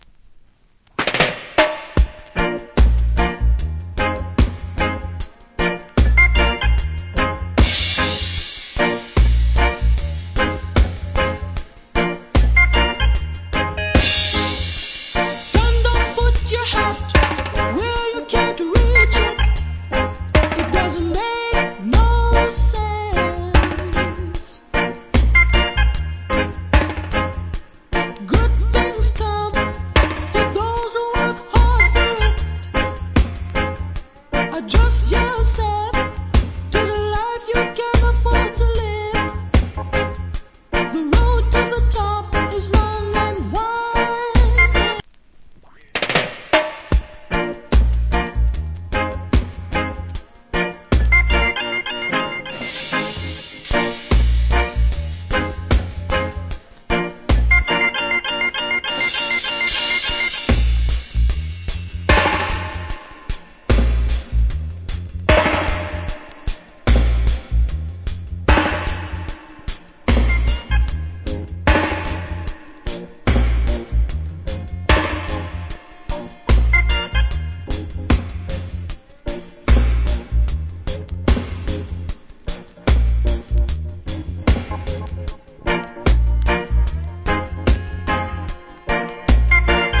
Nice Roots Vocal.